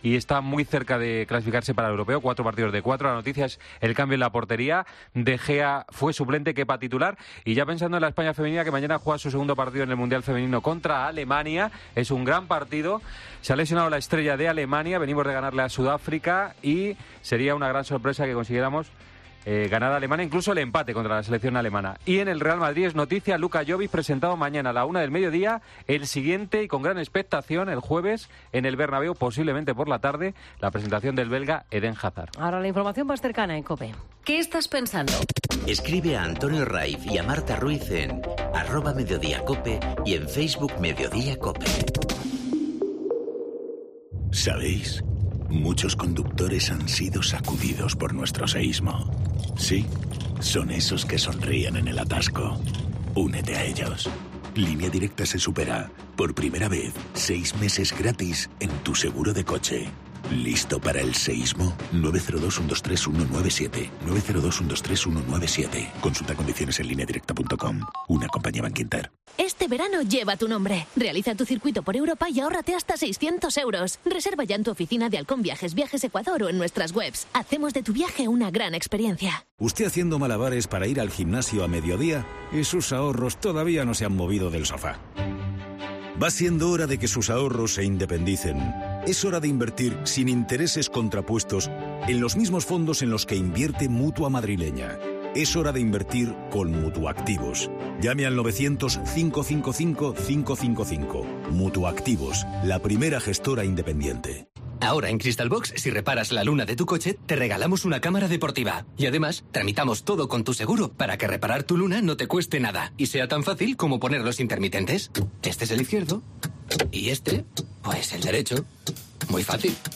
Informativo Mediodía 11 junio 14:20h